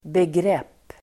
Uttal: [begr'ep:]